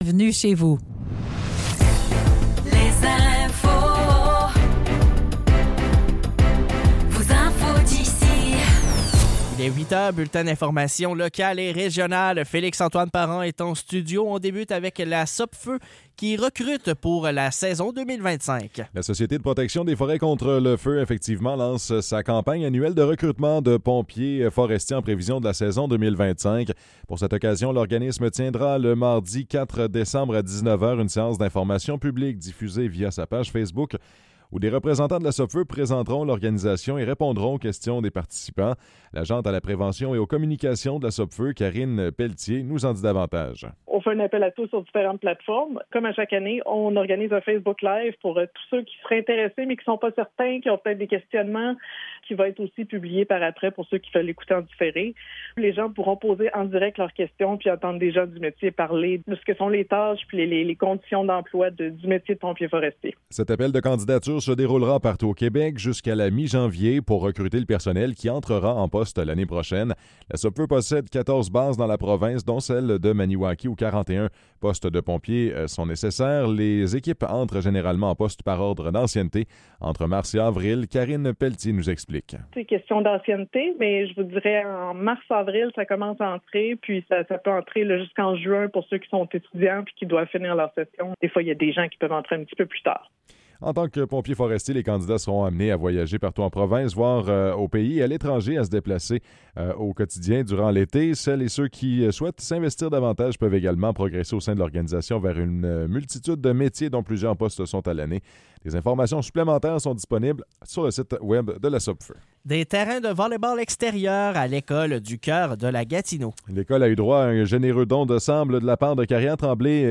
Nouvelles locales - 14 novembre 2024 - 8 h